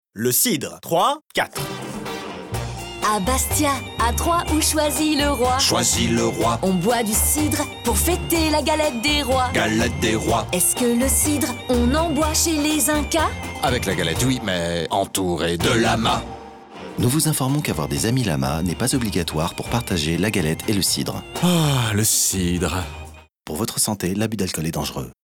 Chanteuse
Humoristique, Parodique